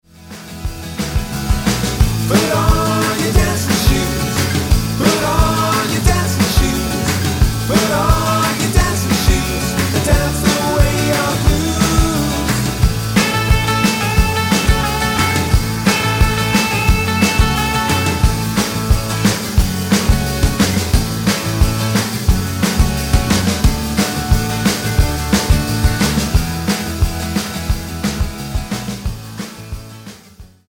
Tonart:A mit Chor